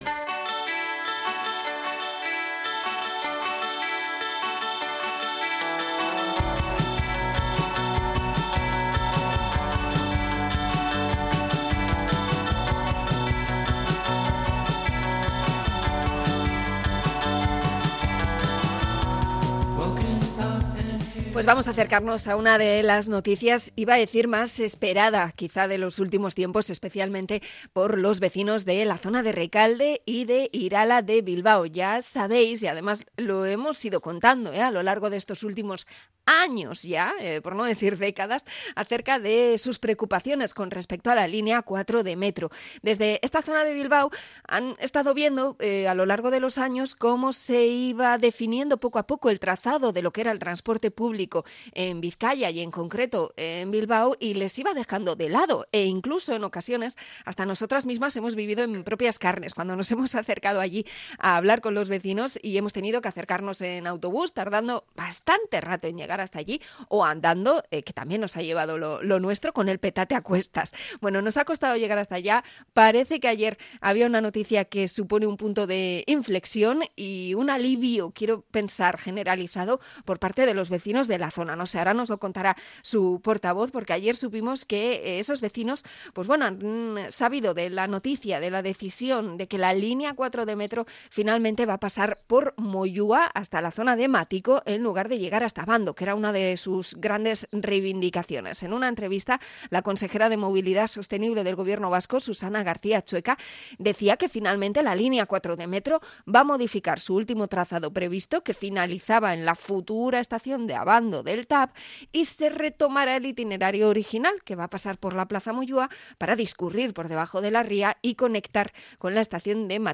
Entrevista a los vecinos de Rekalde e Irala por la línea 4 de metro